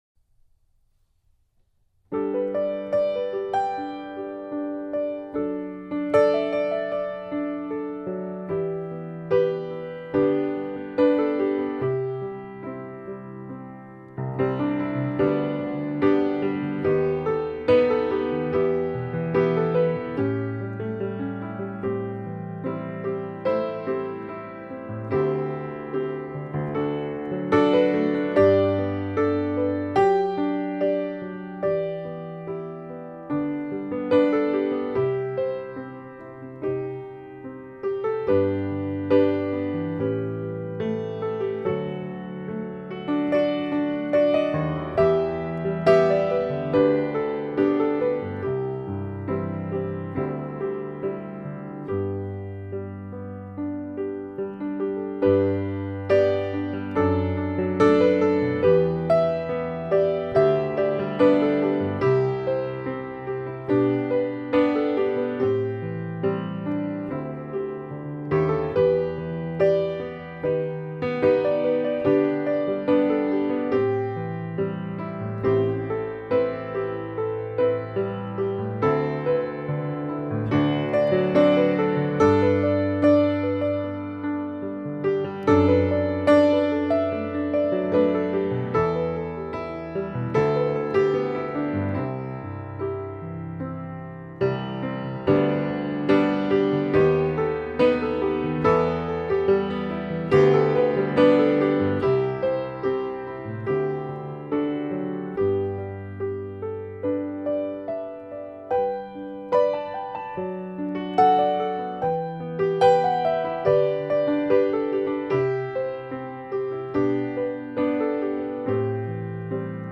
original piano music with a romantic touch